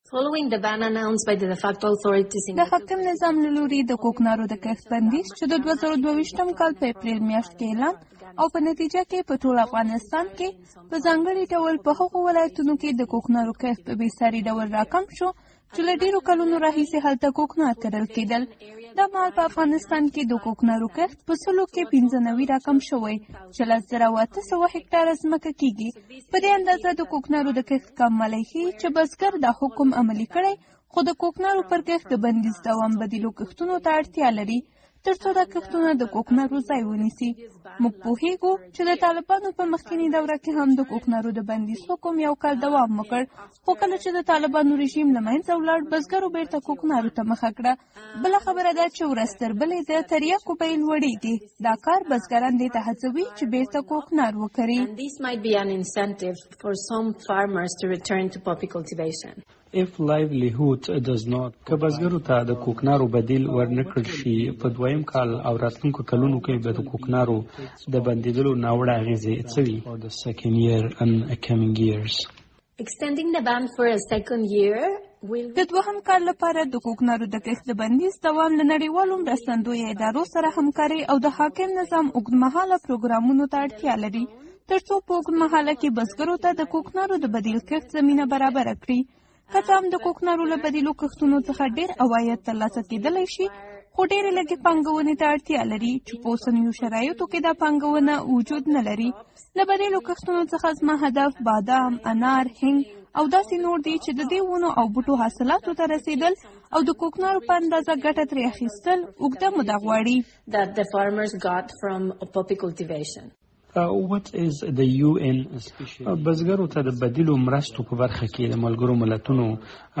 د کوکنارو په اړه مرکه